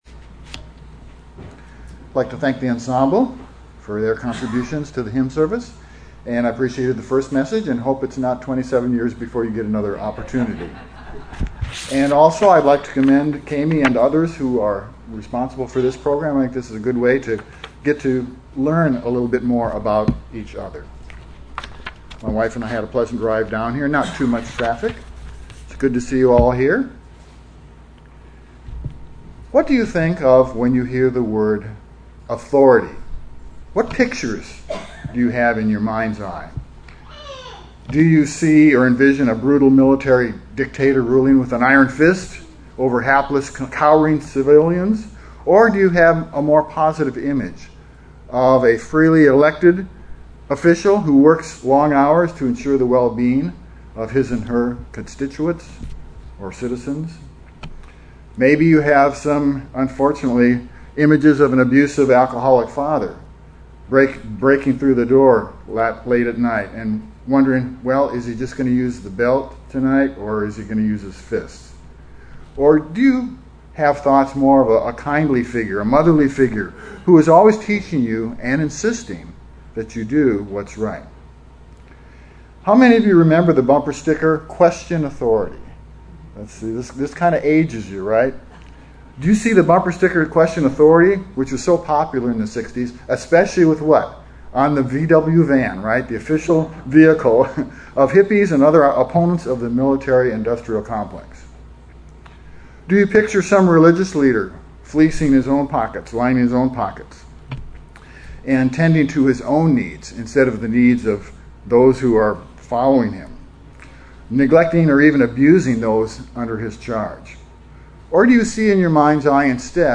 Given in Fort Worth, TX